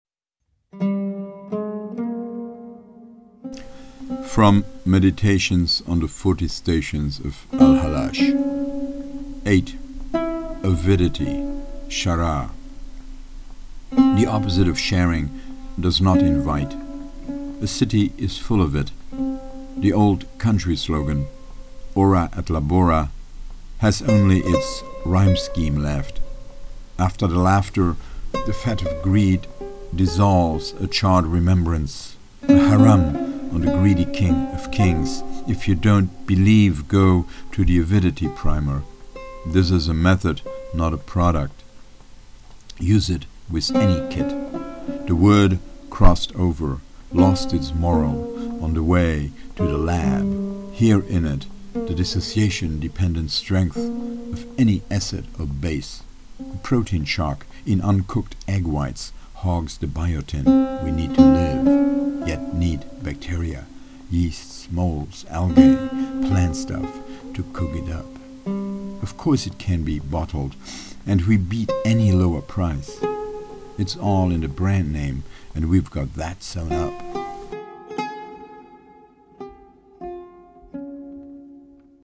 Oud